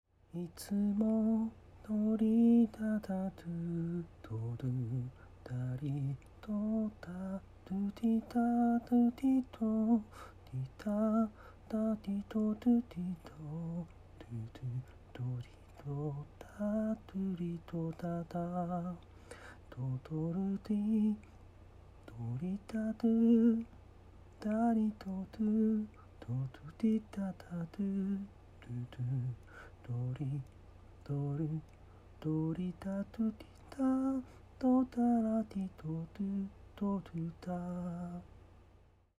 元の鼻歌